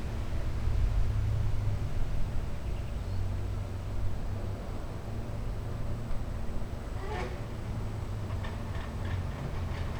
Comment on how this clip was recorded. urban